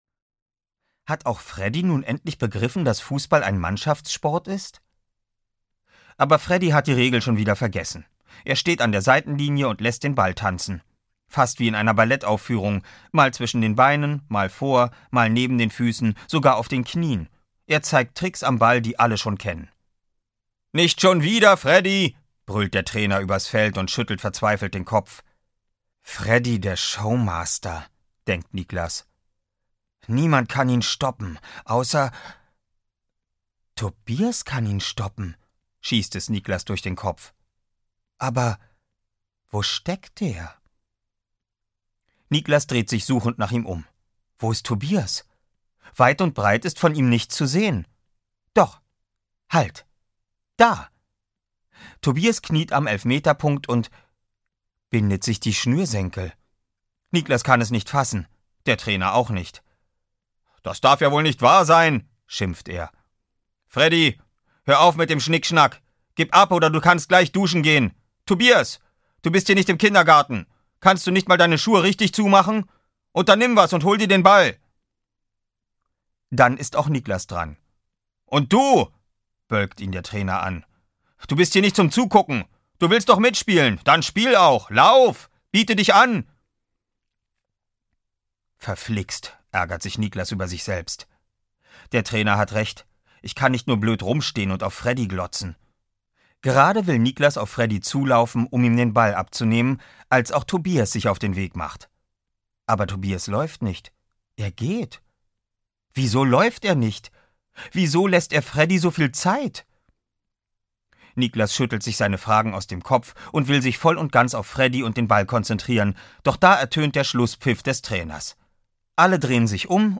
Fußball und ... 1: Fußball und sonst gar nichts! - Andreas Schlüter - Hörbuch